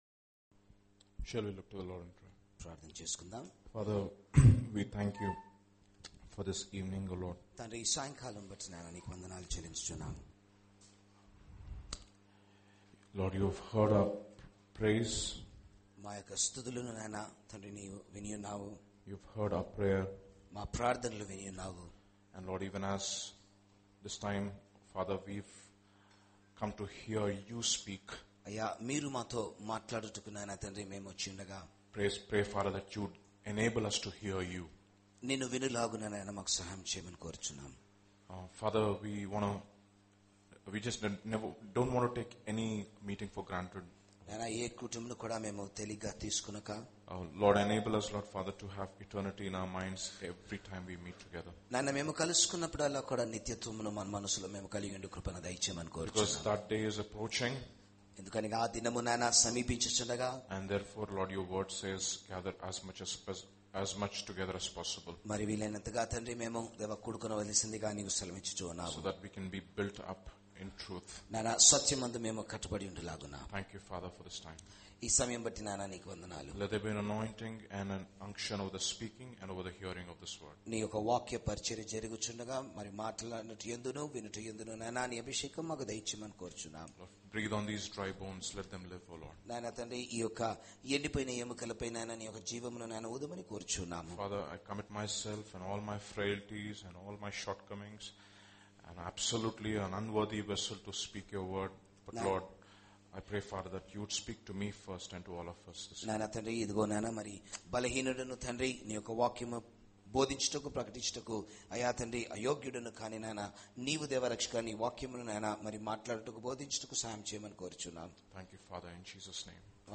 Do you want to become a pillar in the house of God. A sermon